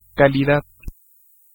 Ääntäminen
IPA : /ˈkwɒl.ɪ.ti/ IPA : /ˈkwɑl.ɪ.ti/